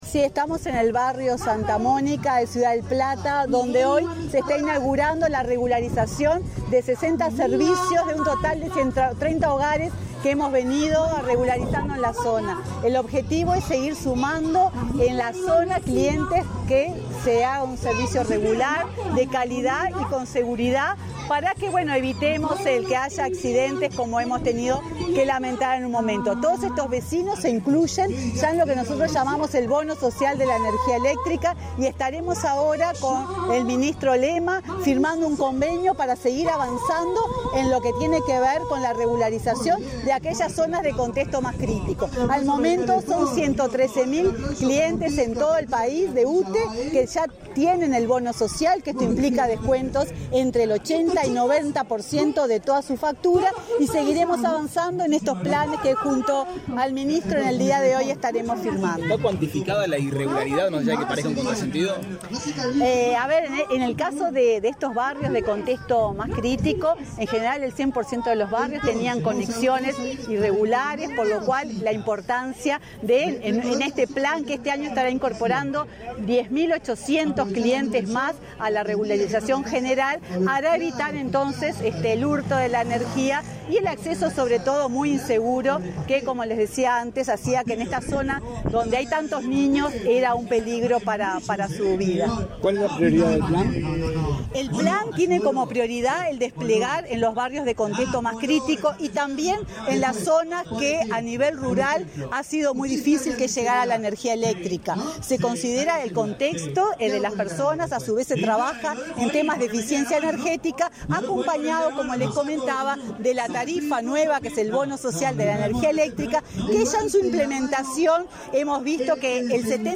Declaraciones a la prensa de la presidenta de UTE, Silvia Emaldi
Declaraciones a la prensa de la presidenta de UTE, Silvia Emaldi 16/03/2022 Compartir Facebook X Copiar enlace WhatsApp LinkedIn Tras participar en la firma del convenio entre UTE y el Ministerio de Desarrollo Social, este 16 de marzo, para facilitar el acceso de los hogares en situación vulnerable a energía eléctrica en forma segura, la presidenta de la empresa estatal, Silvia Emaldi, efectuó declaraciones a la prensa.